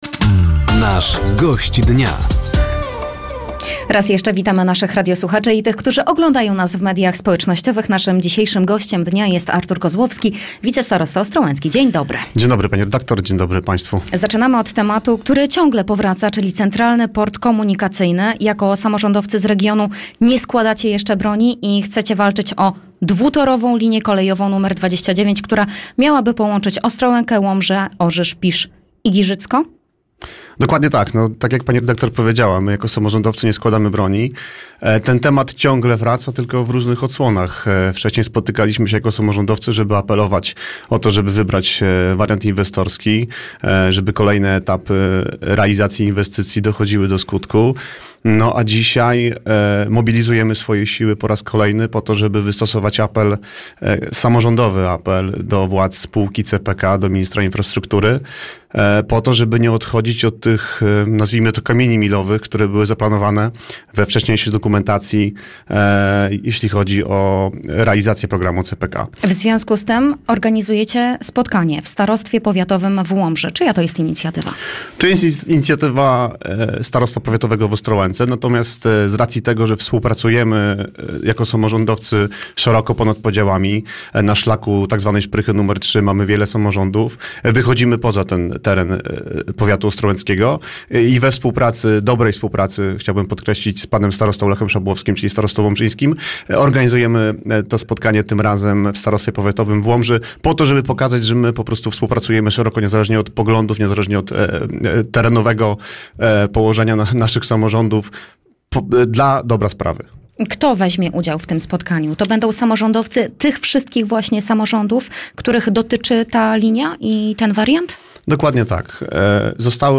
Zapraszamy do wysłuchania rozmowy z Arturem Kozłowskim, wicestarostą ostrołęckim.